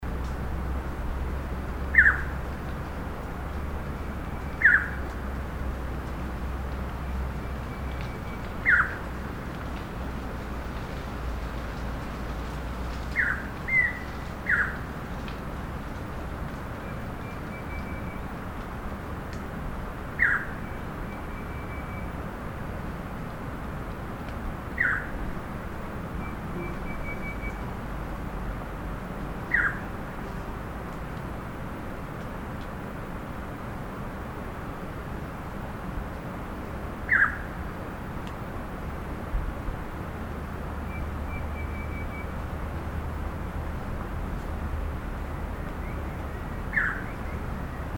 Chant enregistré le 08 mai 2012, en Chine, province du Fujian, réserve de Dai Yun Shan.